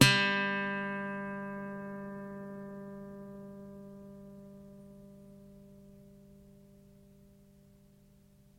小型木吉他 " 学生吉他Twang E
描述：在小规模原声吉他的琴桥上拨动开弦，用USB麦克风直接录制到笔记本电脑上。
标签： 吉他 音符 规模
声道立体声